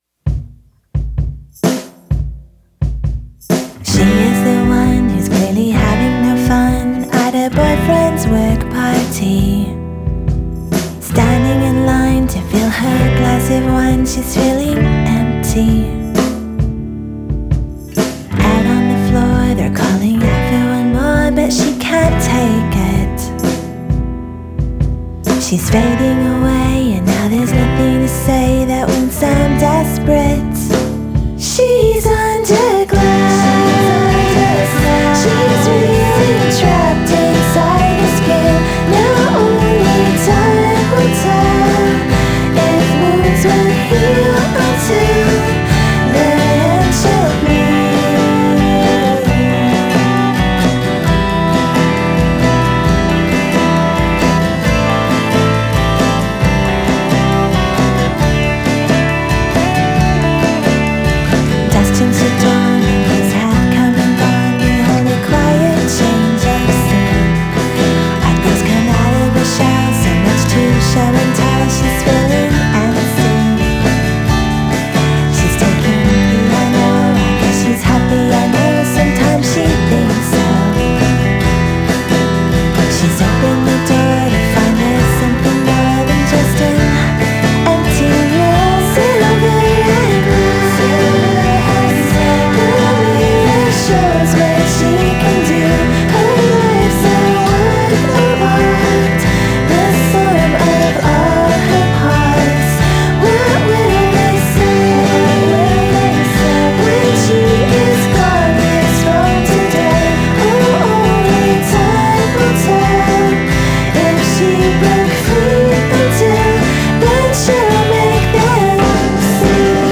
vocals, acoustic guitar
drums
bass
viola, percussion, vocals
rhodes piano
Genre: Indie Pop / Twee / Female Vocal